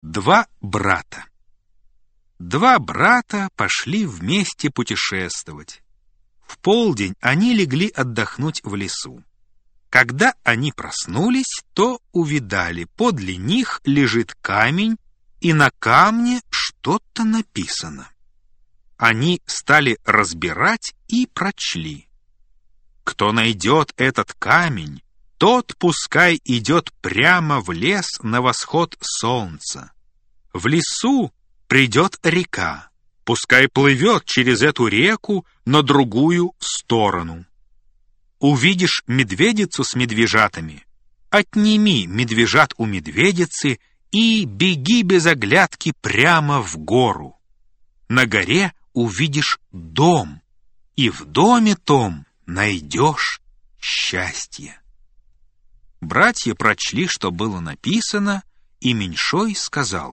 Аудиокнига Сказки русских писателей | Библиотека аудиокниг
Aудиокнига Сказки русских писателей Автор Сборник Читает аудиокнигу Вениамин Смехов.